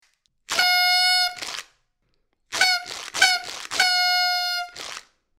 Звуки праздничной дудки
Яркие и задорные мелодии создадут атмосферу радости и беззаботности.
Праздничная дудка играет прекрасные мелодии